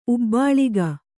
♪ ubbāḷiga